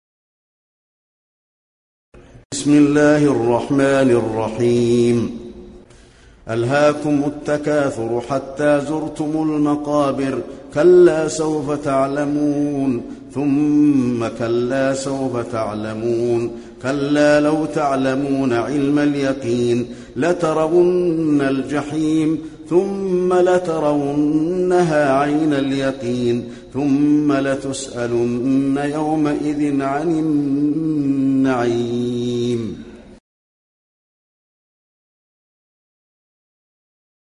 المكان: المسجد النبوي التكاثر